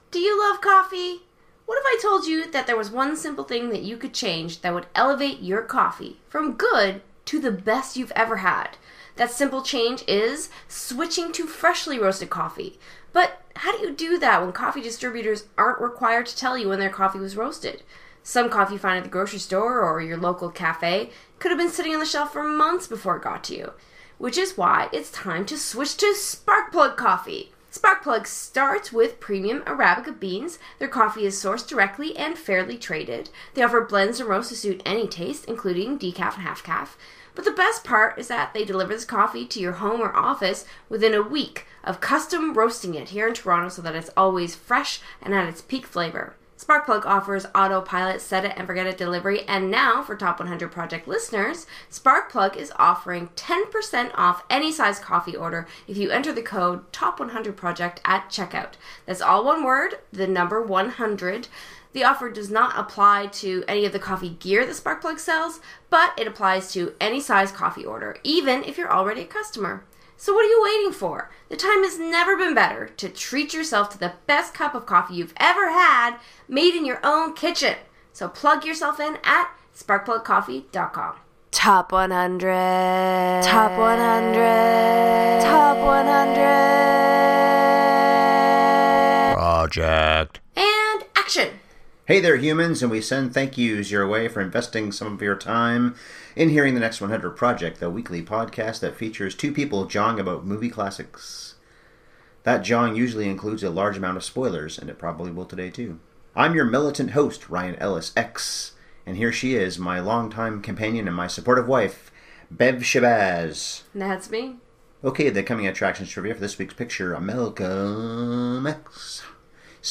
Our dog Sam chose to snore through the entire podcast, but you won’t!